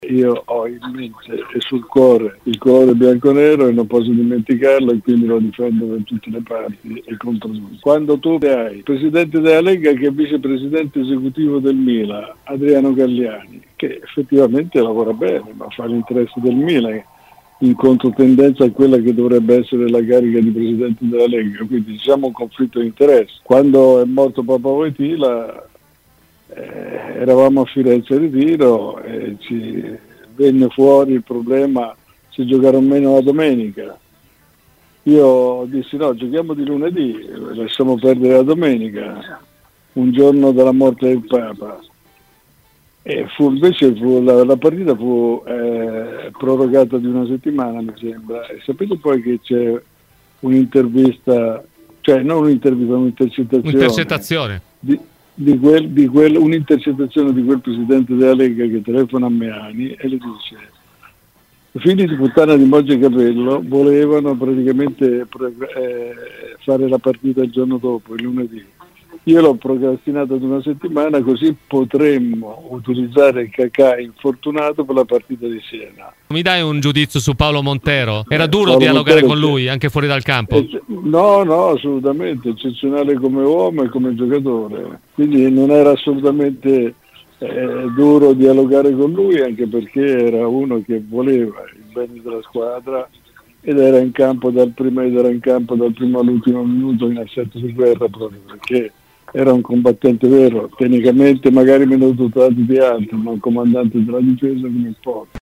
Intervenuto ai microfoni di Radio Bianconera, nel corso di ‘Cose di Calcio’, l’ex dirigente della Juventus Luciano Moggi ha commentato così questo difficile momento per l’Italia: “Io votato come miglior dirigente?